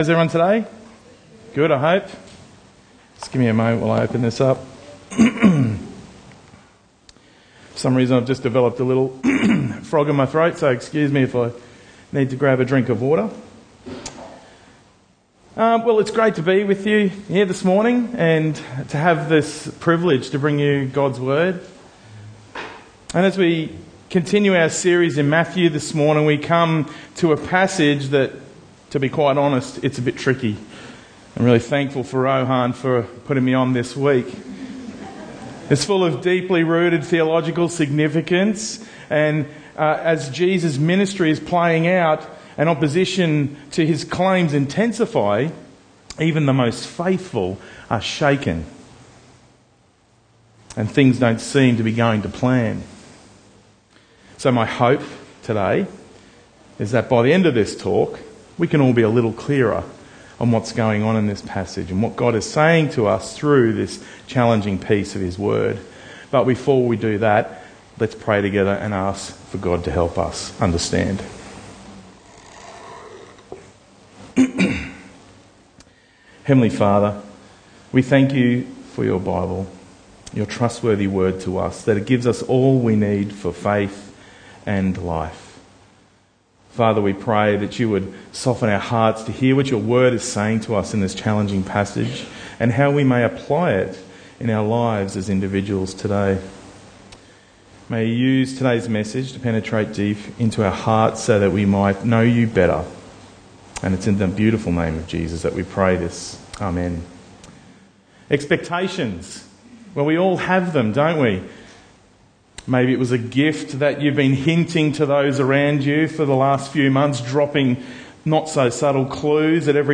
Bible Talks Bible reading: Matthew 11:1-19